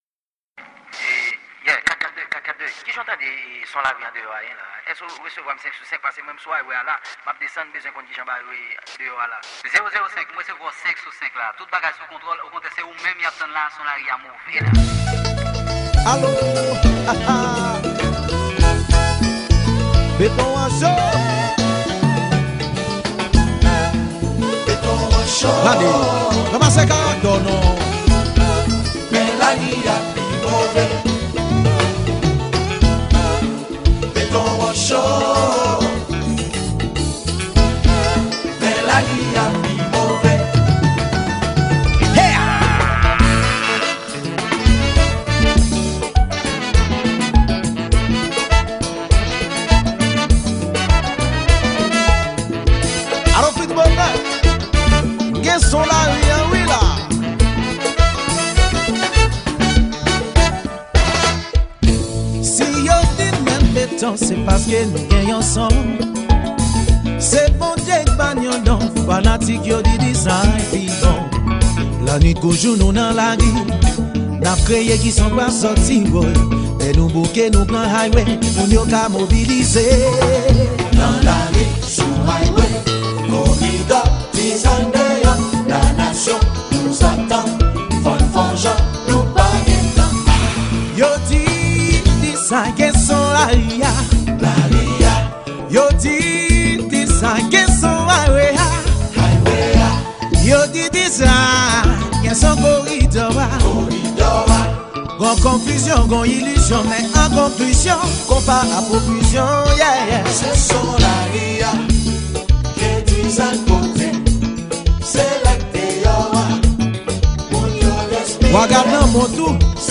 Genre: Konpa